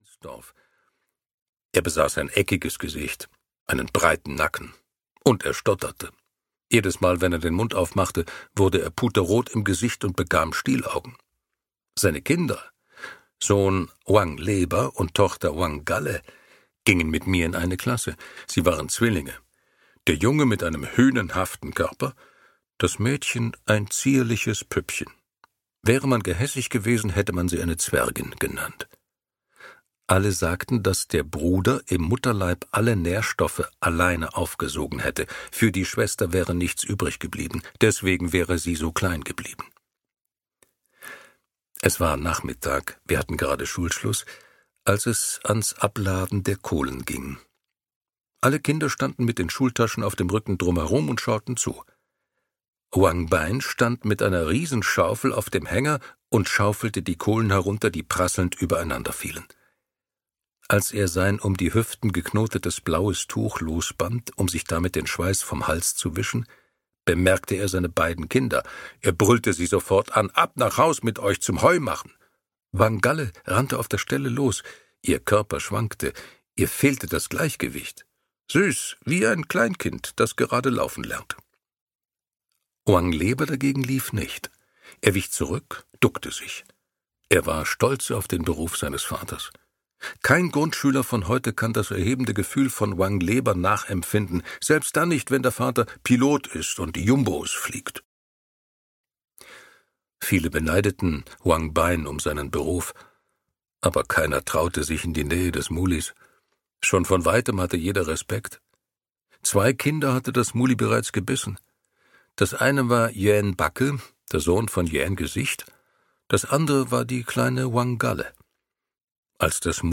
Frösche - Mo Yan - Hörbuch